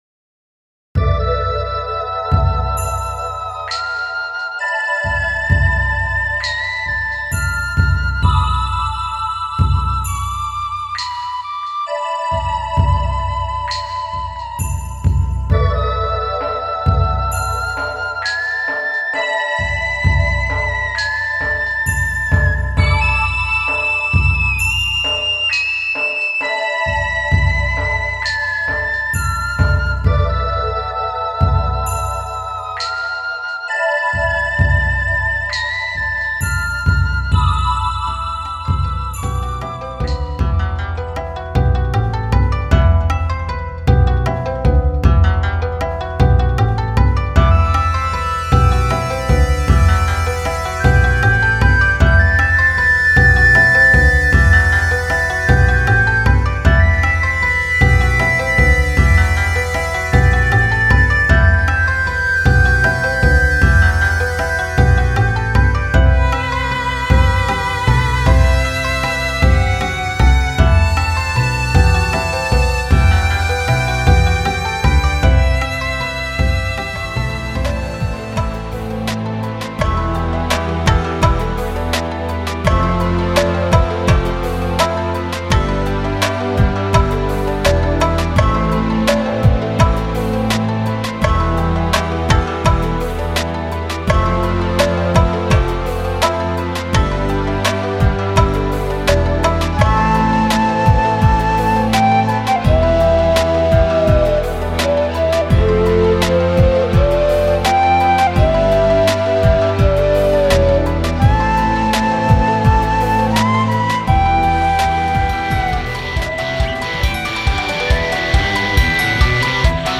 a special medley and Japanese rock tracks.